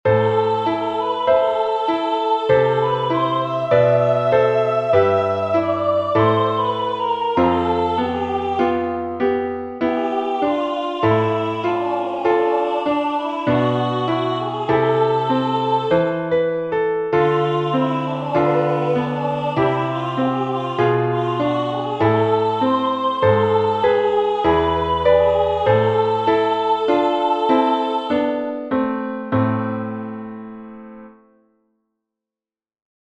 Entoación con acompañamento
Tendes os audios para practicalas, con e sen o piano.
Melodía e acompañamento: